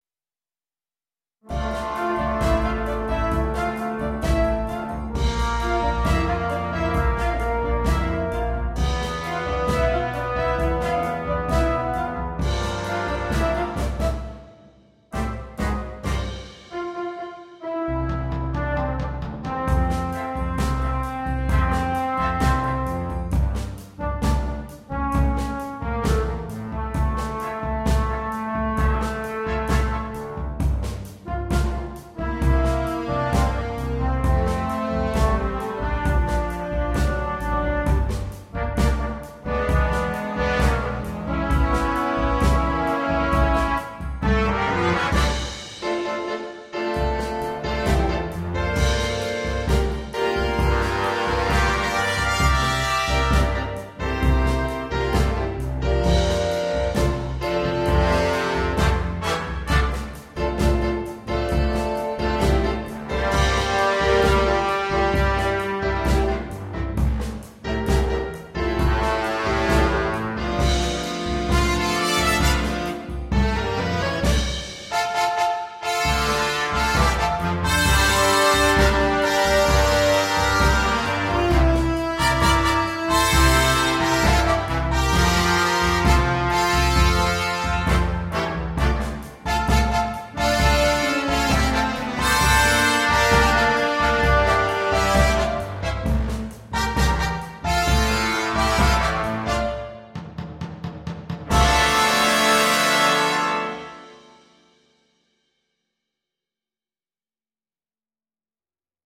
для биг-бэнда.